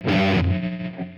Guitar Rnb 2.wav